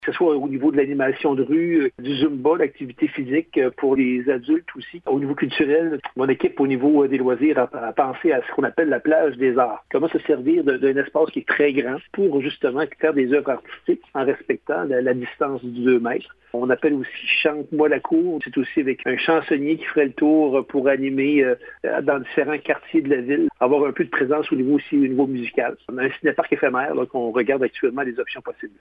Le maire de la municipalité, Jérôme Landry :